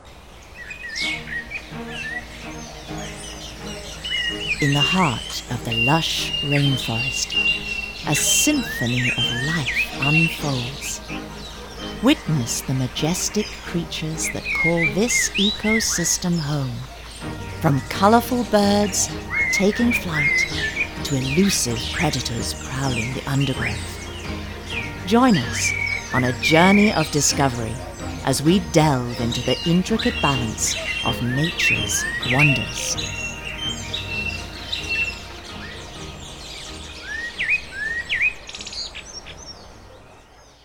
British English Speaker with a wide range of regional and international accents. Young to middle-age.
englisch (uk)
Sprechprobe: Sonstiges (Muttersprache):
NATURE DOCUMENTARY TRAILER.mp3